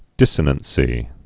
(dĭsə-nən-sē)